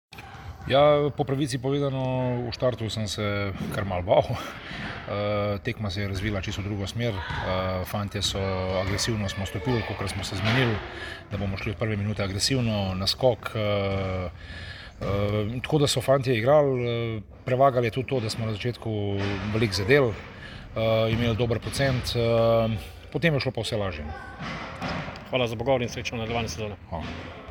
Izjava po tekmi: